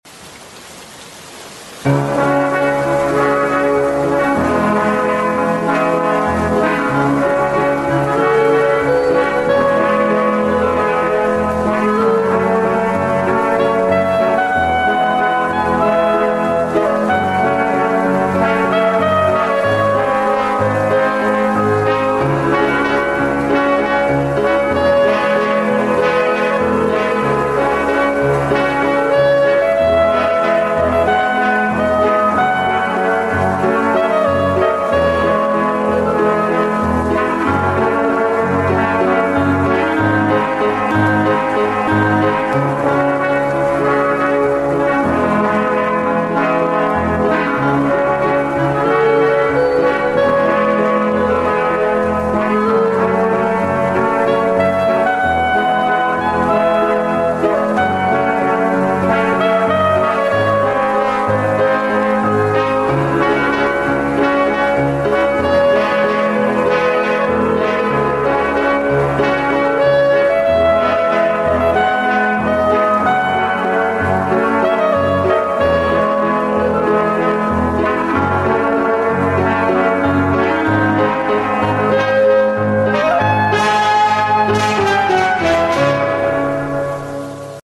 extended slowed + rebverb